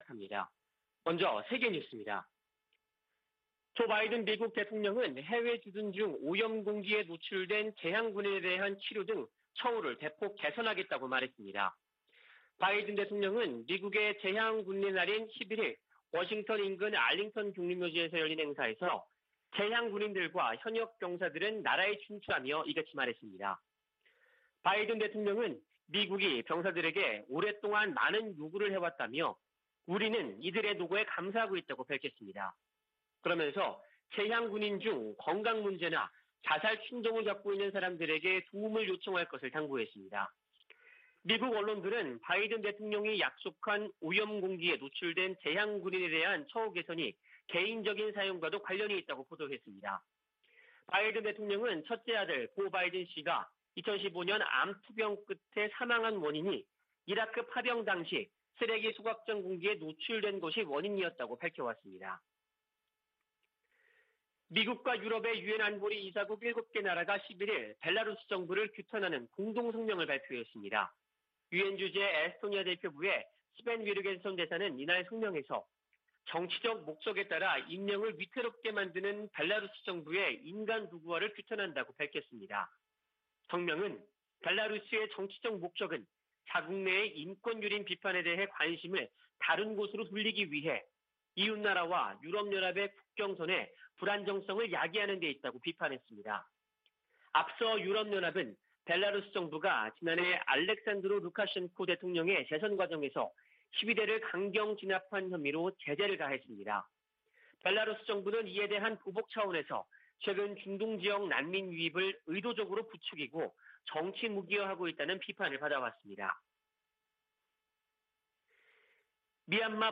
VOA 한국어 '출발 뉴스 쇼', 2021년 11월 13일 방송입니다. 미국과 종전선언의 큰 원칙에 합의했다는 한국 외교부 장관의 발언이 나오면서 실제 성사 여부에 관심이 쏠리고 있습니다. 북한이 핵실험을 유예하고 있지만 미사일 탐지 회피 역량 개발에 집중하고 있다고 미국 유력 신문이 보도했습니다. 미국 정부가 캄보디아와 연관된 미국 기업들에 대한 주의보를 발령하면서, 북한의 현지 불법 활동에 대해서도 주의를 당부했습니다.